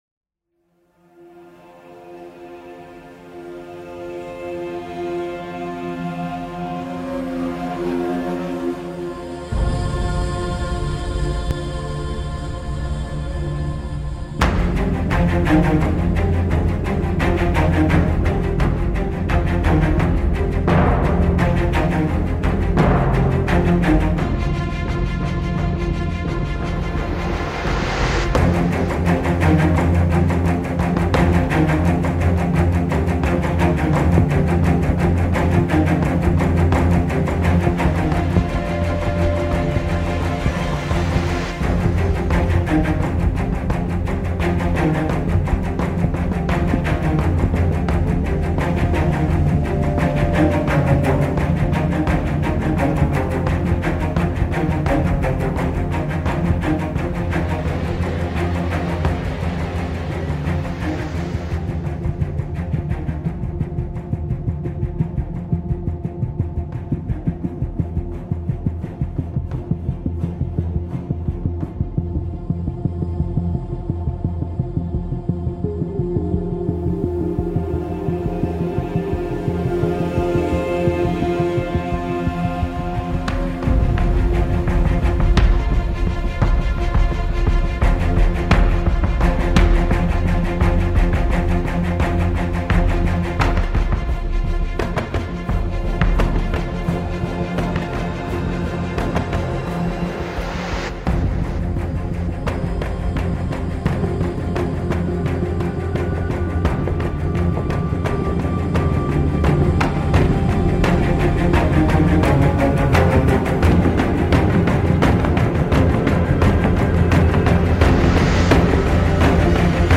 Action track for RPG and looter shooter.
Action string driven pulse track for RPG and looter shooter.